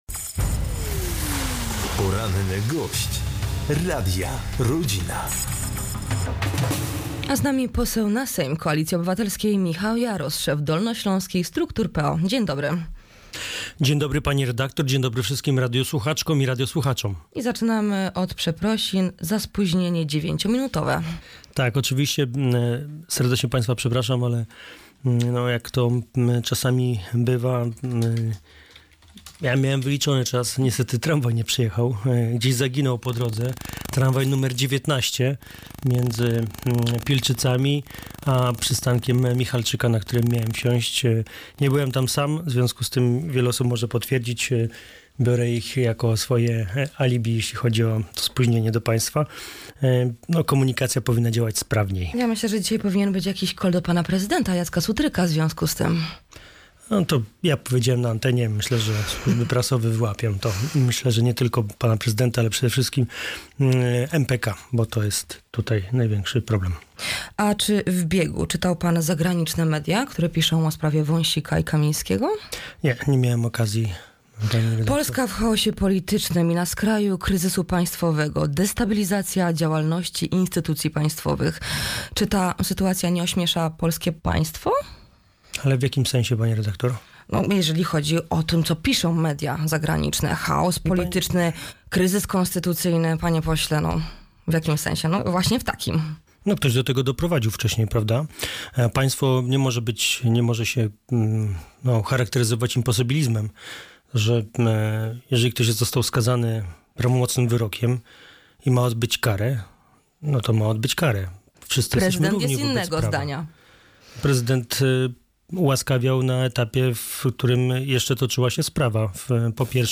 Sprawę komentuje w audycji „Poranny Gość” poseł na Sejm Koalicji Obywatelskiej Michał Jaros – szef dolnośląskich struktur PO.